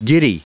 Prononciation du mot giddy en anglais (fichier audio)
giddy.wav